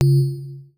Universal UI SFX / Clicks
UIClick_Tonal Resonance Button 02.wav